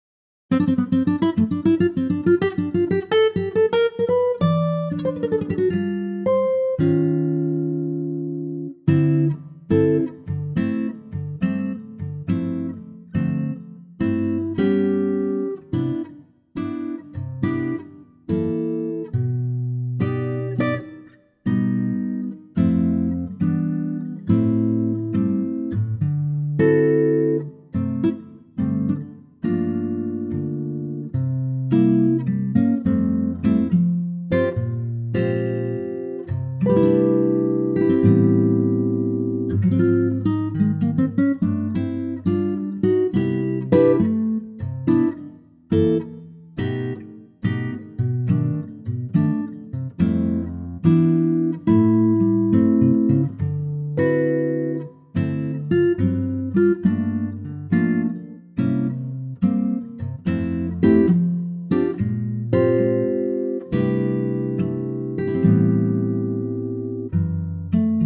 key - C - vocal range - C to C